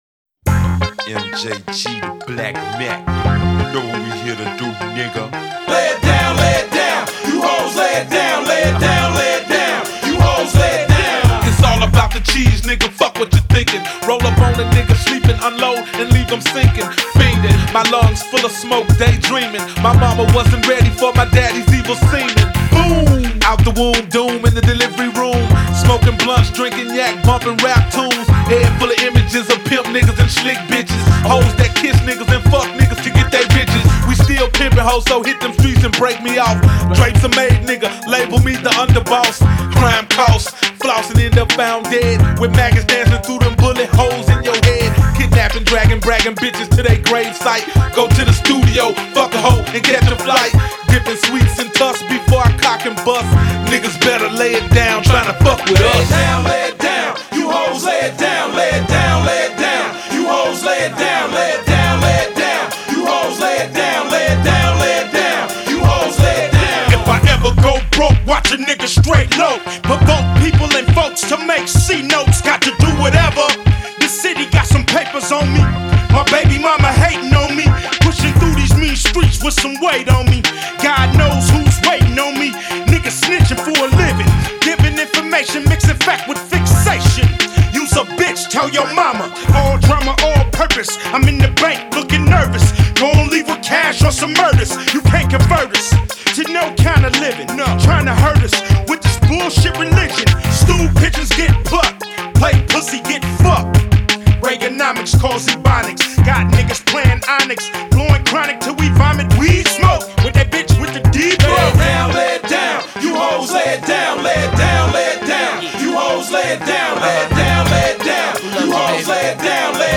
Genre: Memphis Rap.